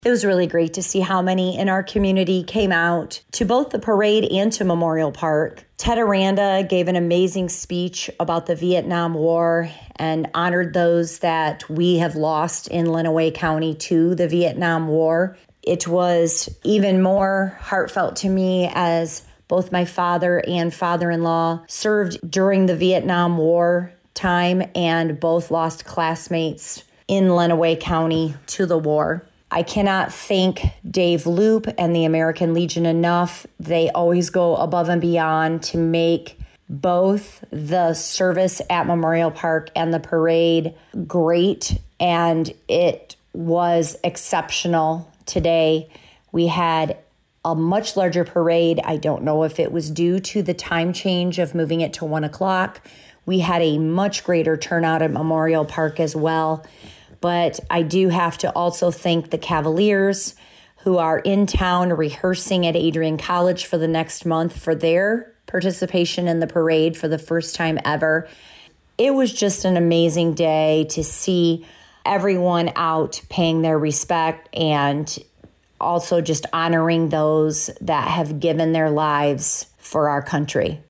Adrian Mayor Angie Heath talked to WLEN News about the events.